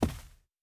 Footstep Carpet Walking 1_10.wav